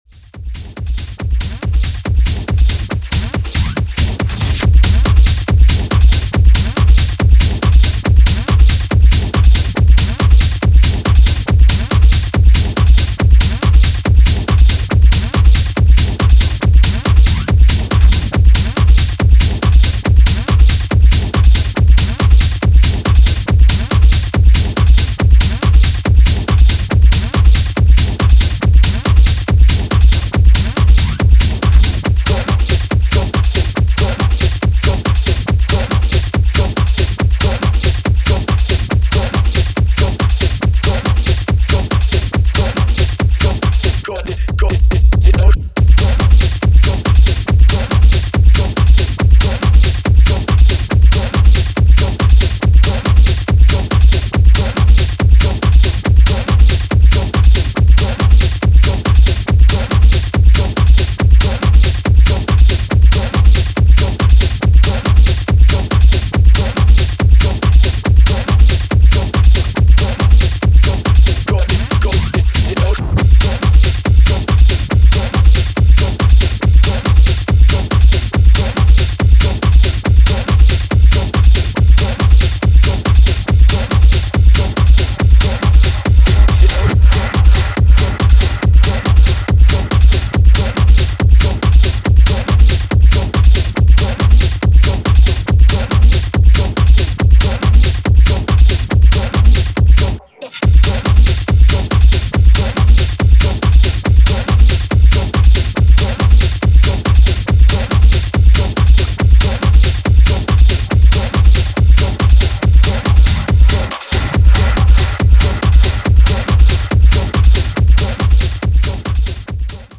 a vocal centered track with an old school vibe.
old school groovy signature sound